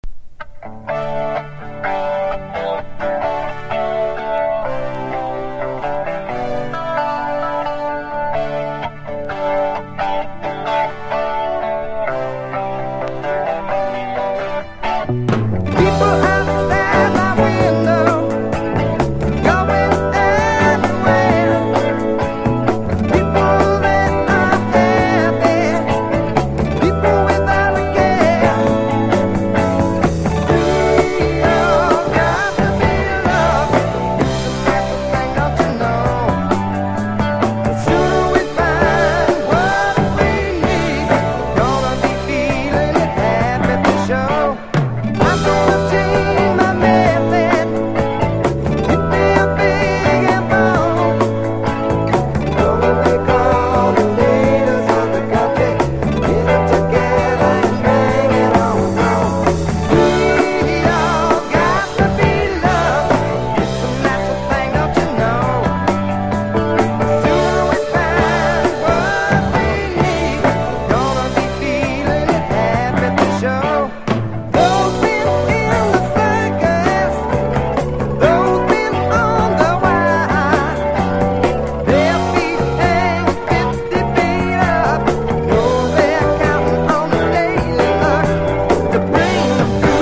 1. 70'S ROCK >
AOR